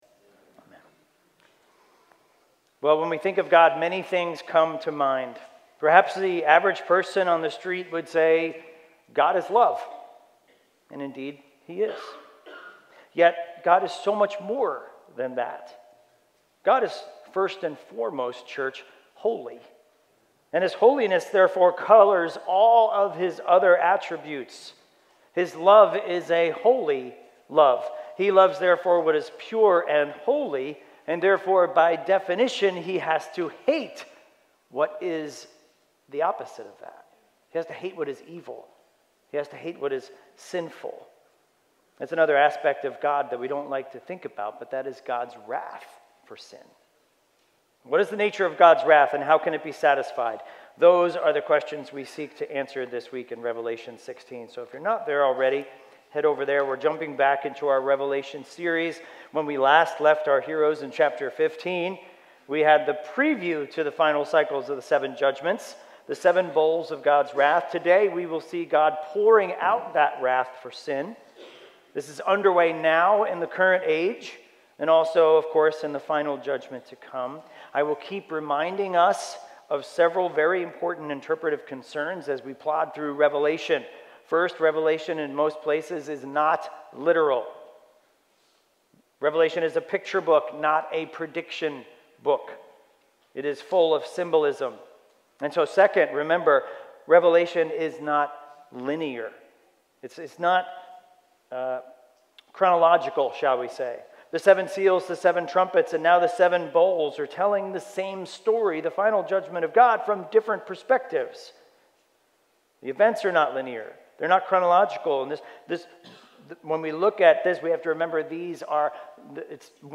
A message from the series "Genesis 1-11." In Genesis 9:1-29 we learn that God rebuilds what sin has destroyed.